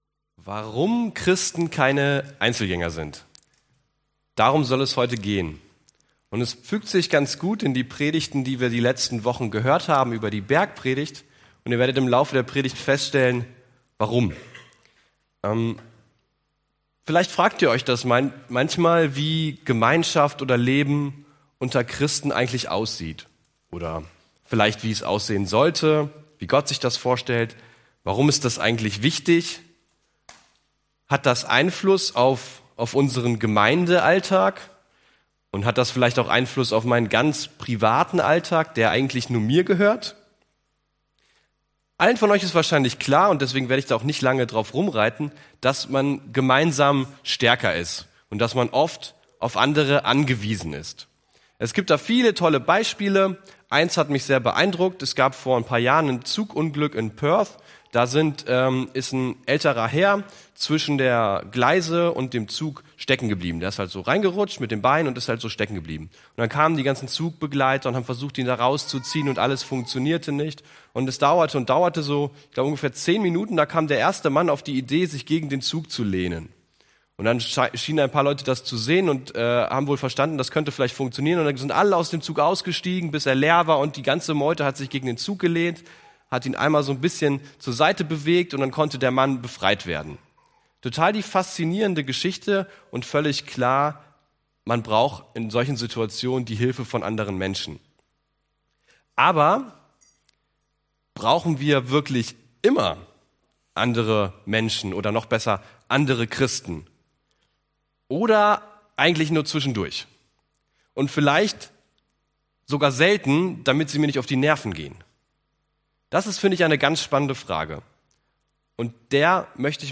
Februar 2020 Warum Christen keine Einzelgänger sind Prediger(-in)